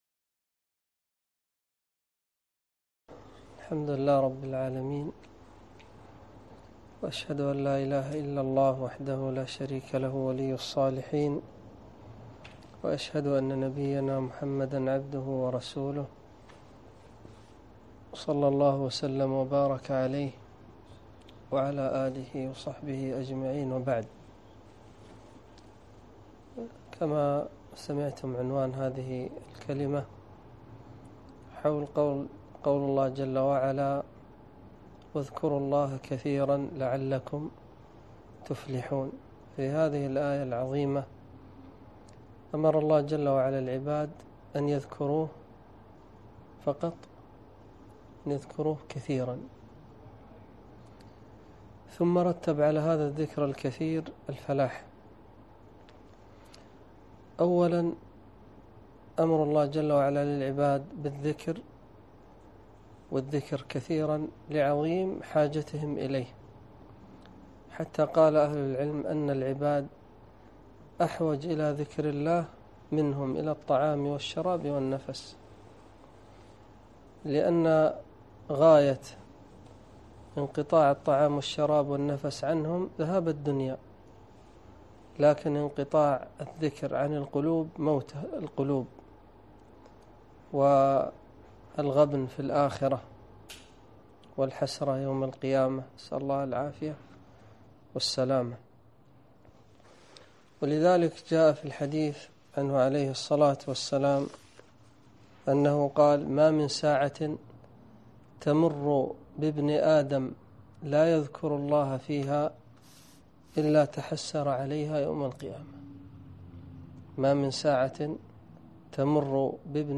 محاضرة - (واذكروا الله كثيرًا لعلكم تفلحون)